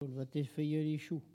Collectif-Patois (atlas linguistique n°52)